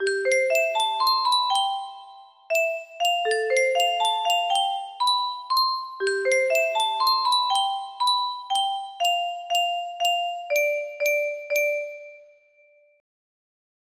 (Official? musicbox version of LP's theme)